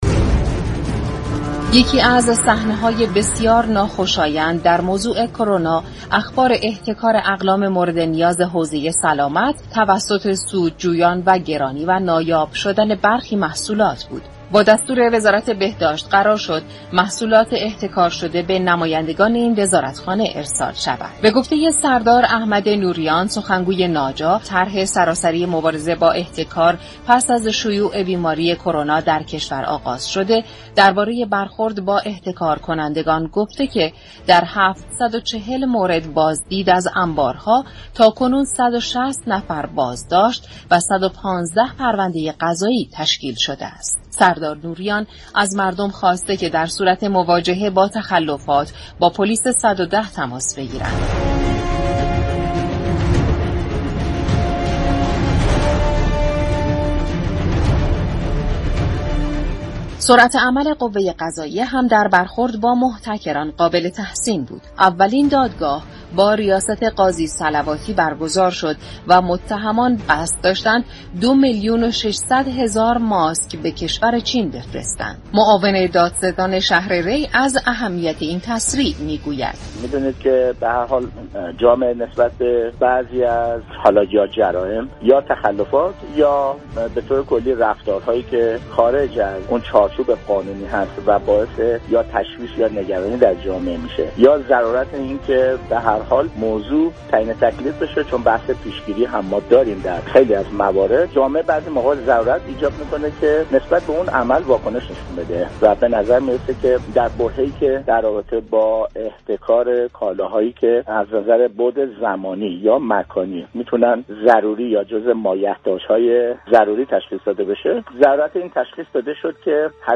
به گزارش شبكه رادیویی ایران، معاون دادستانی شهر ری در برنامه جهان در سالی كه گذشت درباره برخورد قاطع قوه قضاییه با احتكاركنندگان گفت: در برابر برخی جرایم و تخلفات لازم است به كار خود سرعت بخشیم و از این رو دادگاه های احتكار كالای حوزه سلامت را با سرعت بیشتر و به شكل علنی برگزار كردیم.
برنامه جهان در سالی كه گذشت در تعطیلات نوروز هر روز ساعت 16:30 از رادیو ایران پخش شد.